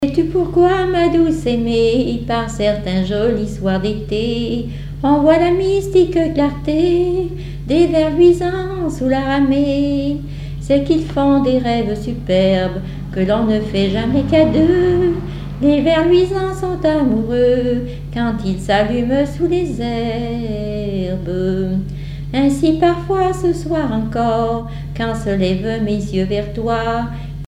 Genre strophique
chansons et témoignages parlés
Pièce musicale inédite